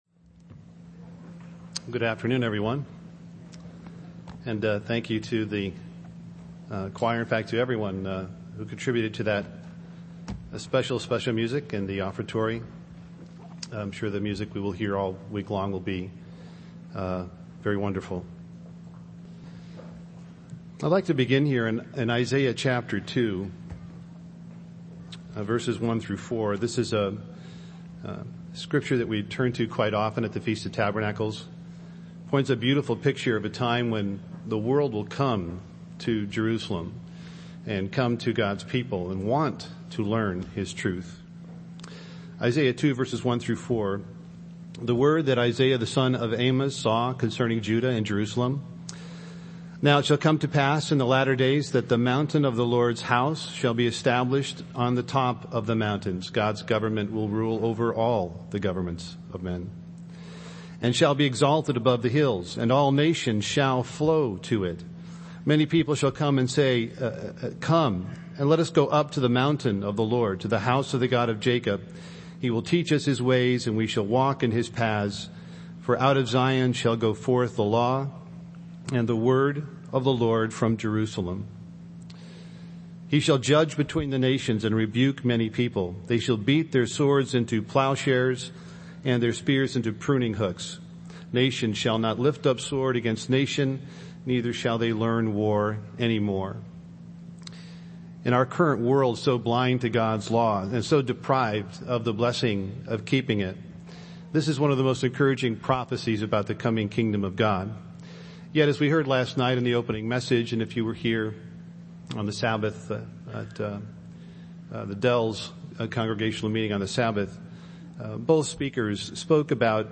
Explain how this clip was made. This sermon was given at the Wisconsin Dells, Wisconsin 2015 Feast site.